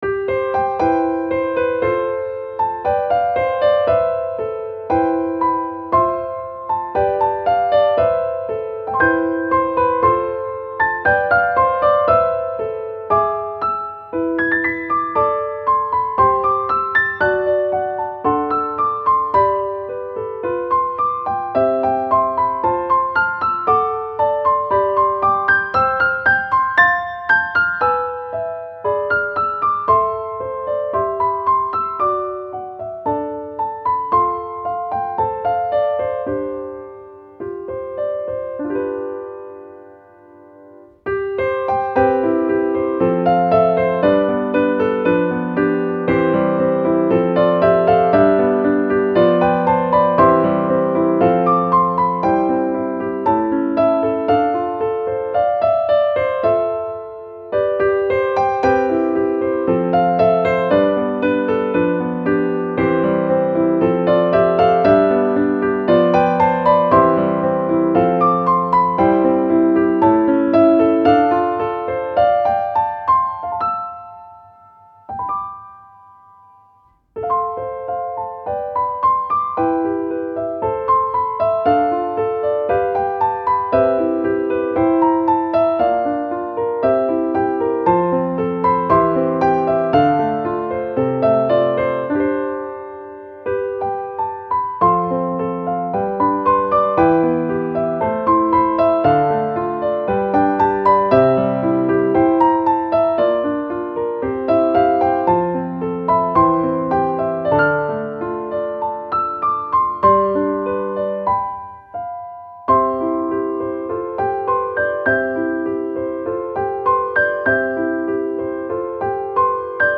• 暗めのしっとりしたピアノ曲のフリー音源を公開しています。
ogg(R) 楽譜 甘美 しっとり かわいい